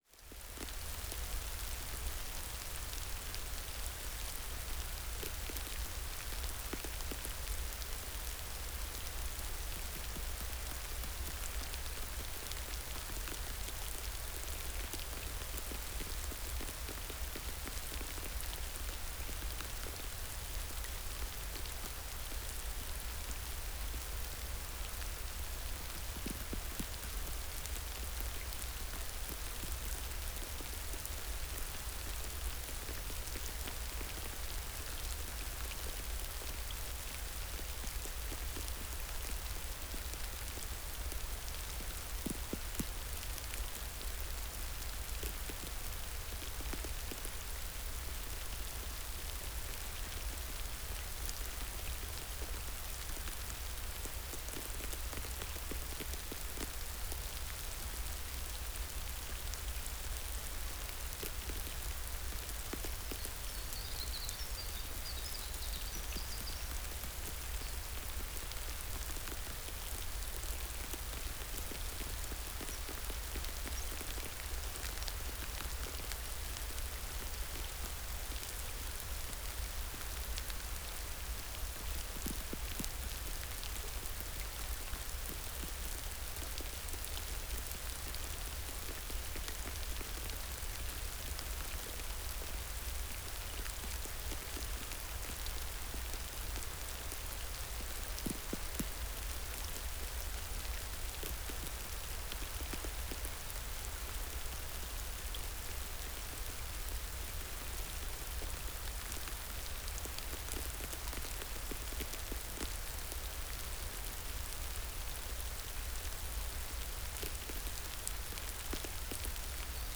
01 - Les bruits du ciel.flac